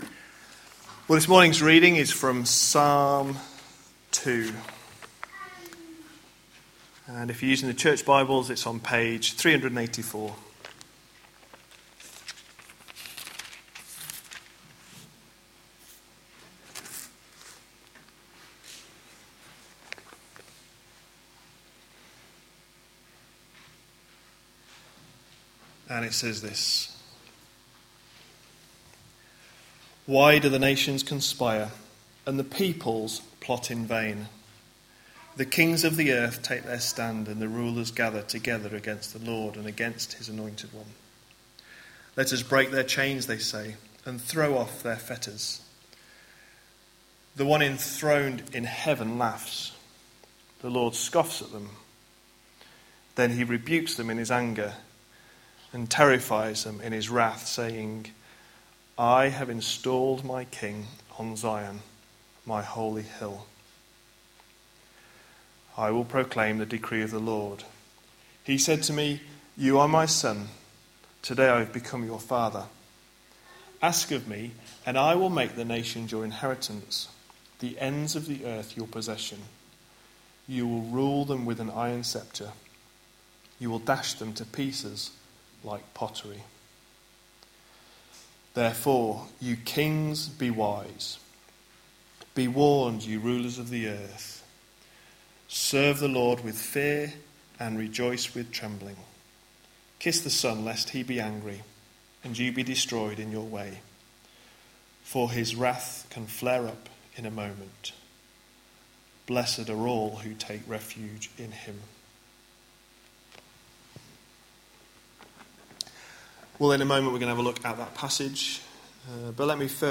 A sermon preached on 3rd January, 2016.